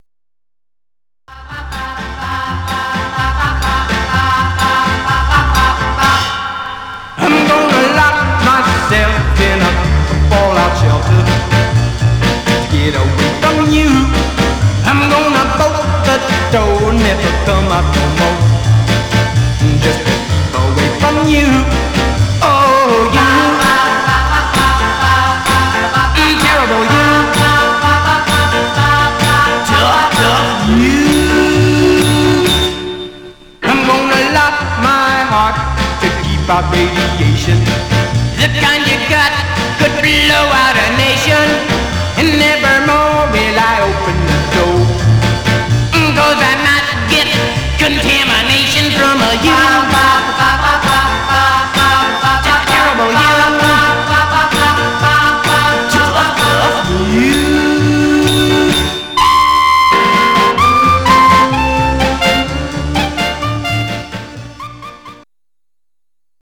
Condition: M- DJ # popcorn, teen
Mono